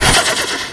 TurboBlow2.wav